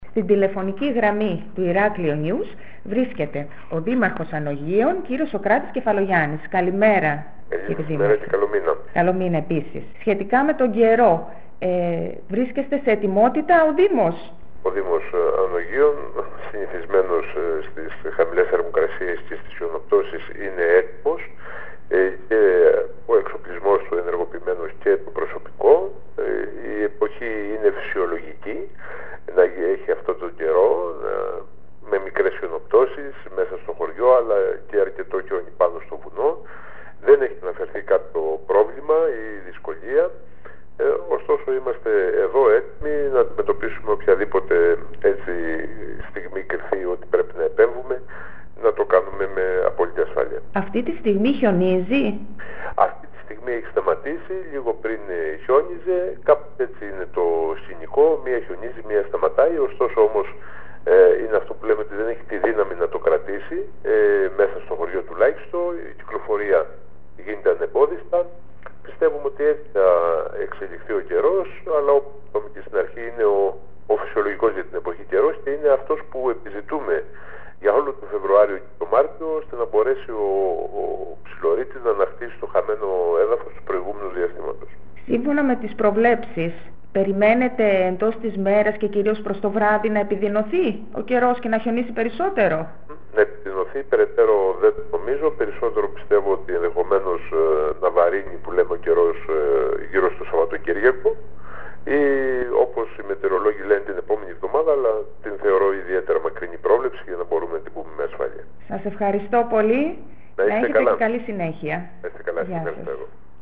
Μιλώντας στο iraklionews ο Δήμαρχος Ανωγείων Σωκράτης Κεφαλογιάννης τόνισε ότι τα φυσιολογικά για την εποχή καιρικά φαινόμενα, αφού δεν είναι ακραία  είναι επιθυμητά για όλο το Φεβρουάριο και το Μάρτιο προκειμένου «να μπορέσει ο Ψηλορείτης να ανακτήσει το χαμένο έδαφος του προηγούμενου διαστήματος».
Ακολουθούν οι δηλώσεις του Δημάρχου Ανωγείων Σωκράτη Κεφαλογιάννη:
montarismeno-2-dimarchos-anogeion-sokratis-kefalogiannis.mp3